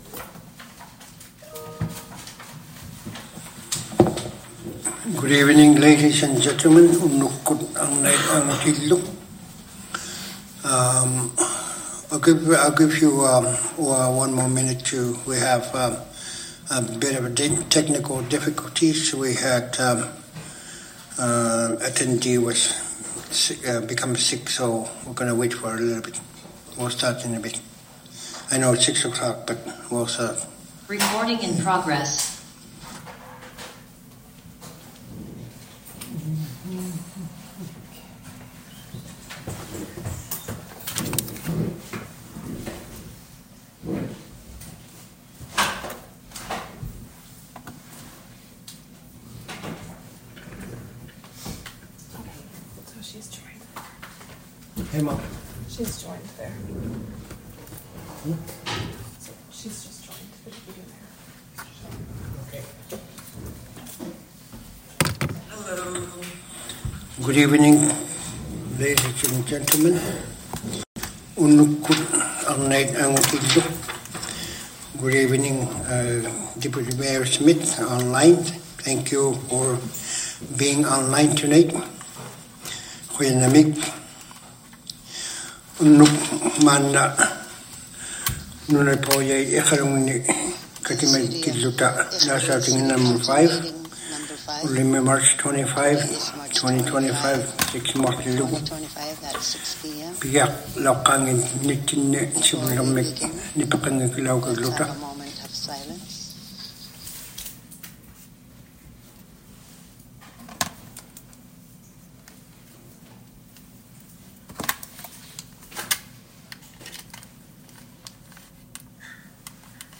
Due to technical issues with our AV system, the audio was recorded on a different device, which unfortunately does not allow us to separate the Inuktitut and English language tracks.